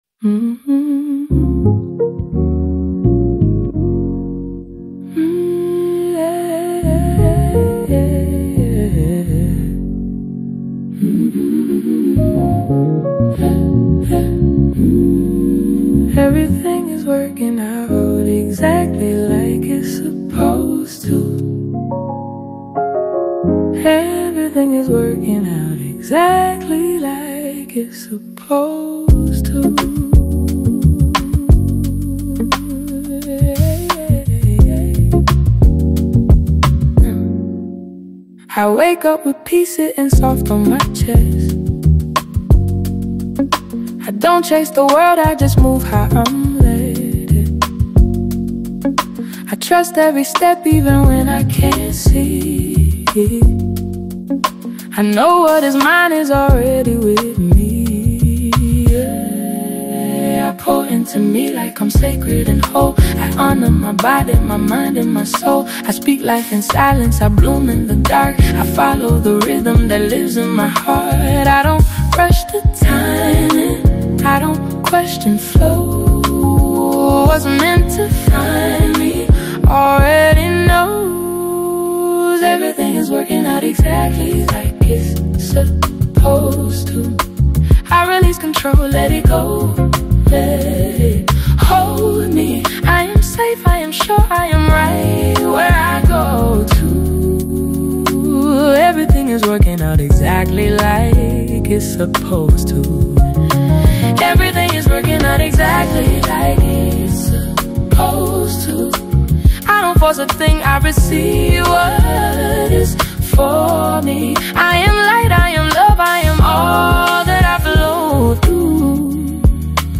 There is a quiet honesty in how this track begins.